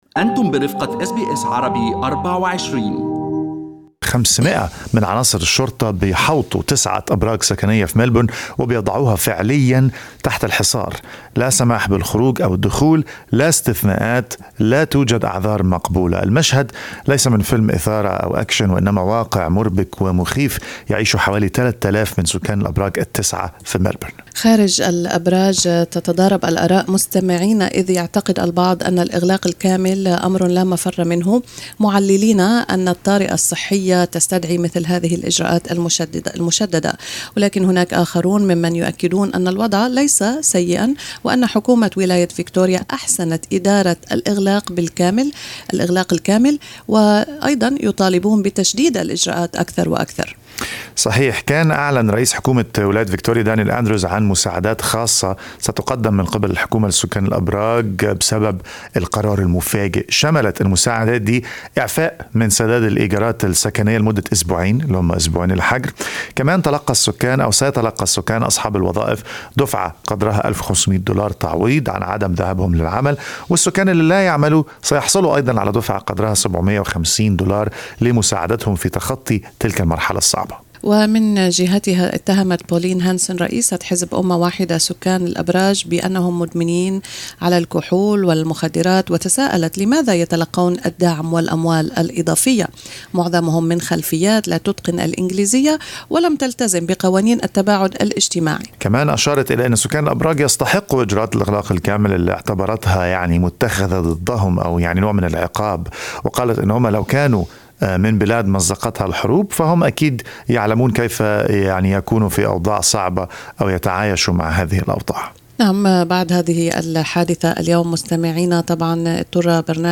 شهادات لسكان أبراج ملبورن، تبين حقيقة الوضع داخل المنطقة الموبوؤة.